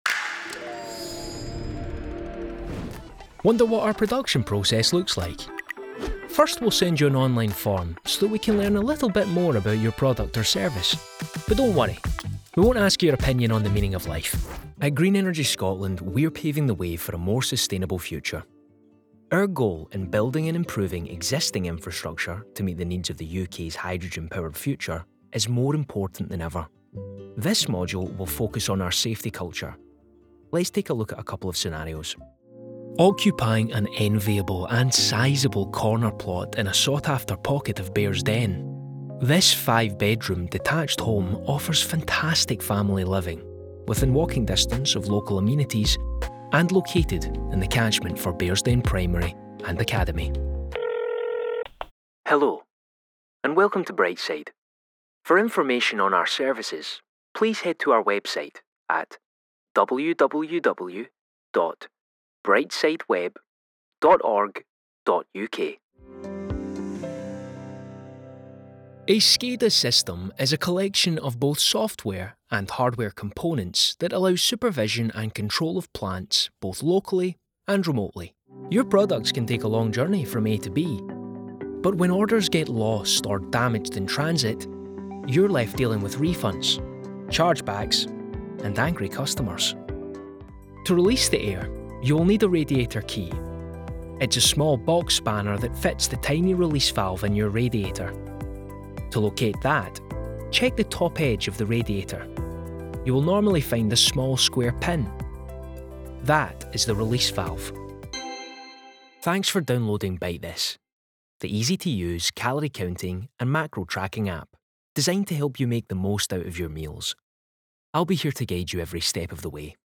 Corporate Showreel
Known for his professionalism and versatility, he delivers a wide range of UK and international accents from his broadcast-quality home studio in Glasgow.
Male
Scottish
Friendly
Warm
Confident